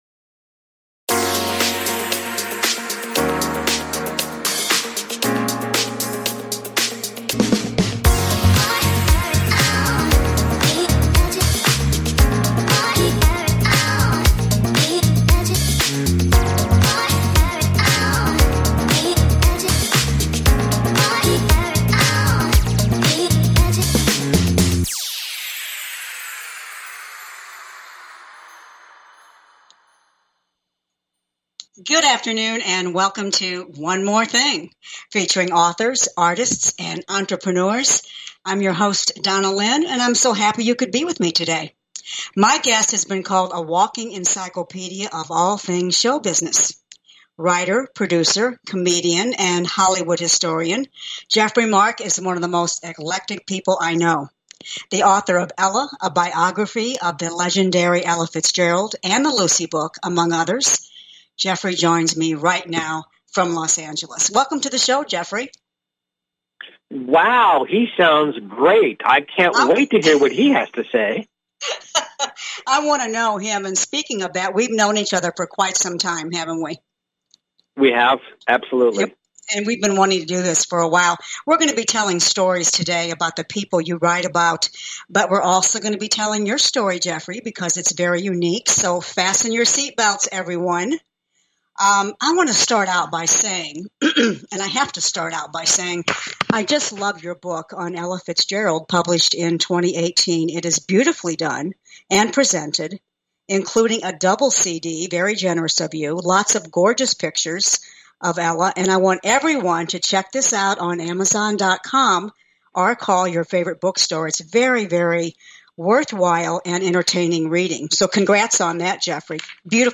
I interview a variety of celebs, artists and authors.